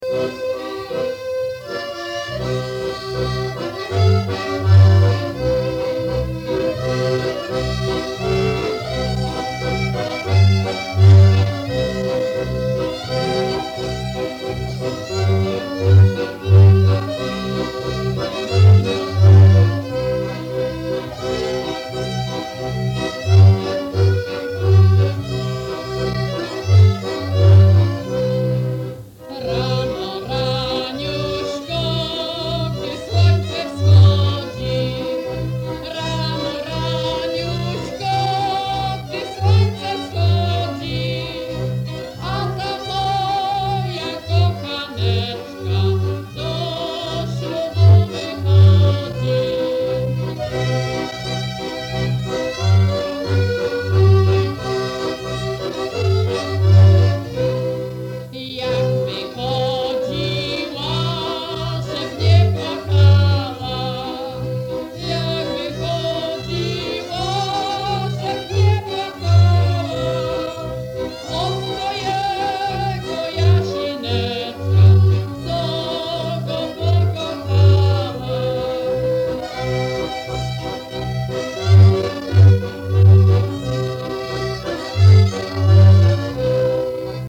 Rano, raniuśko, gdy słońce wschodzi – Żeńska Kapela Ludowa Zagłębianki
Nagranie archiwalne